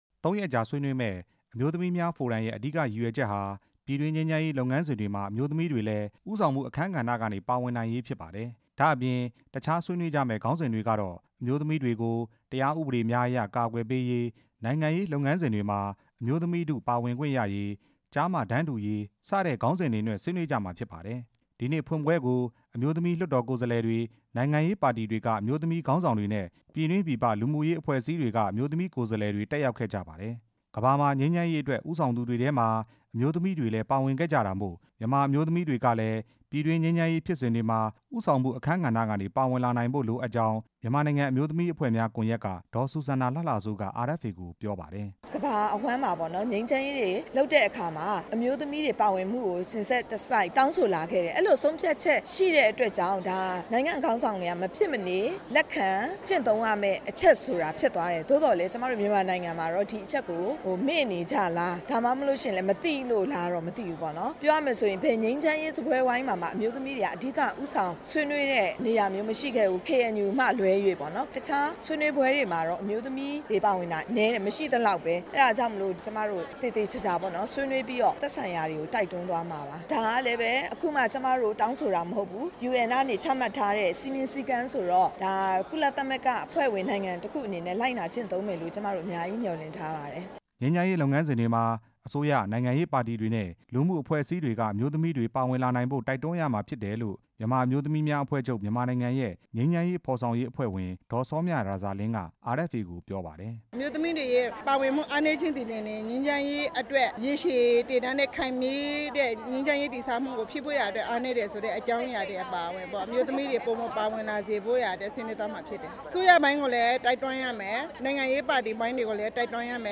အမျိုးသမီးဖိုရမ်အကြောင်း တင်ပြချက်